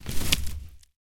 Звуки осьминога: осьминог шевелит щупальцем